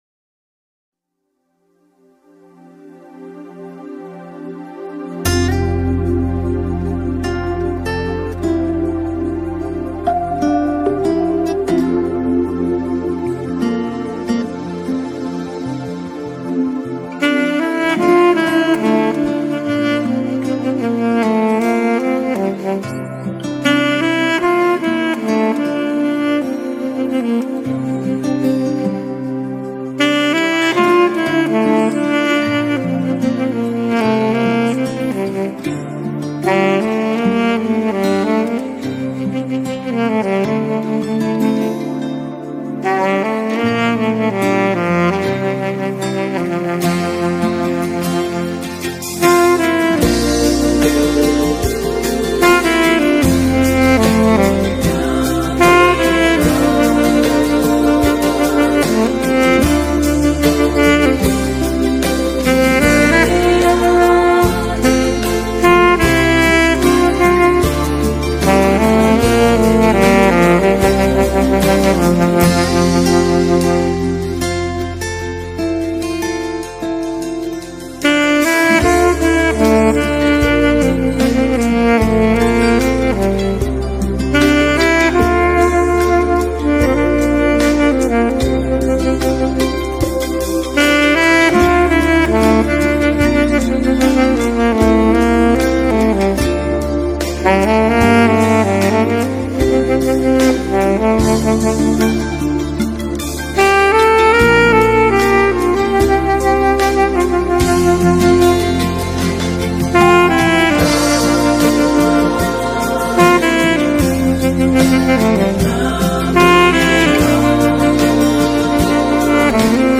Very soulful
instrumental songs of praise and worship
saxophone